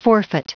Prononciation du mot forfeit en anglais (fichier audio)
Prononciation du mot : forfeit